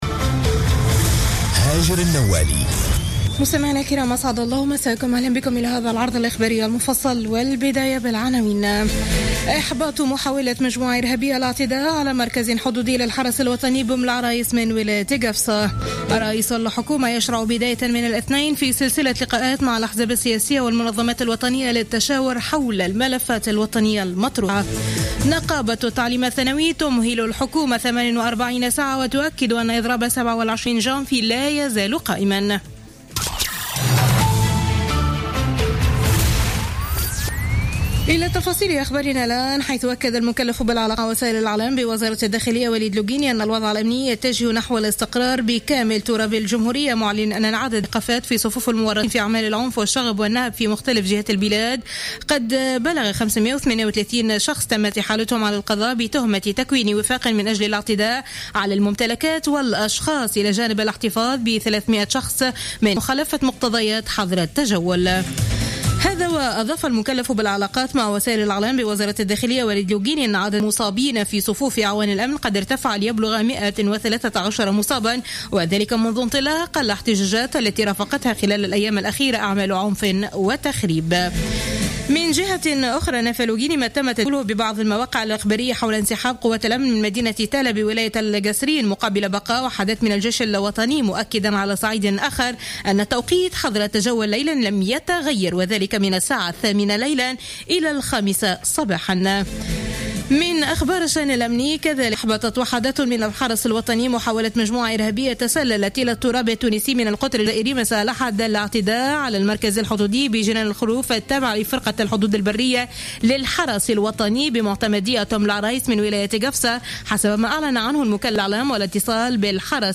نشرة أخبار منتصف الليل ليوم الإثنين 25جانفي 2016